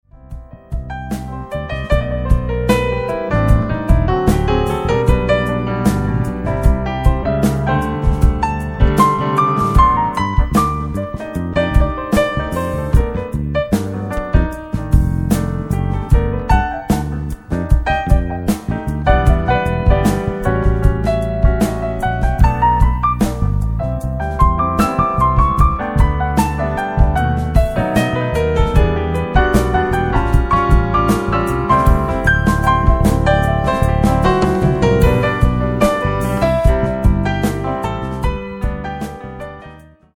PIANO TRIO
甘く儚く、切なげに舞い上がるミラクルなメロディの応酬。